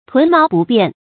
屯毛不辨 tún máo bù biàn
屯毛不辨发音
成语注音ㄊㄨㄣˊ ㄇㄠˊ ㄅㄨˋ ㄅㄧㄢˋ